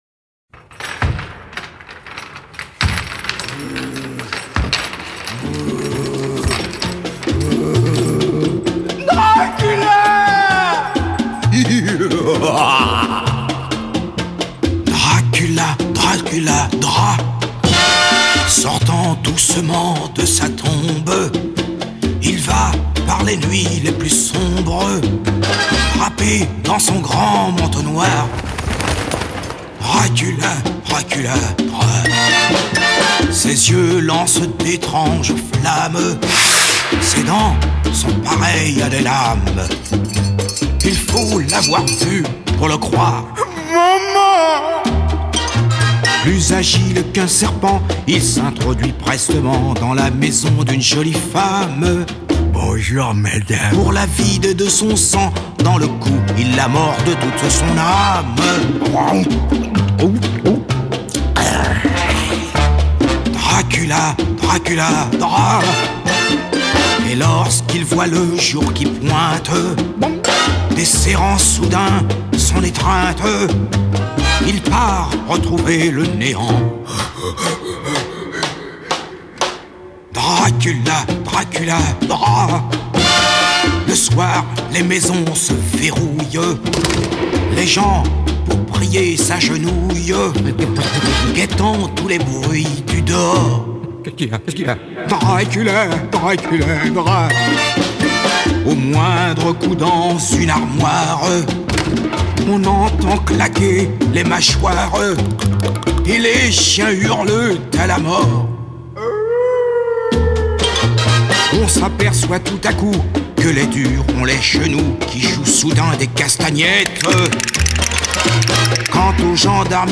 un cha cha cha au sujet du vampire retient l’attention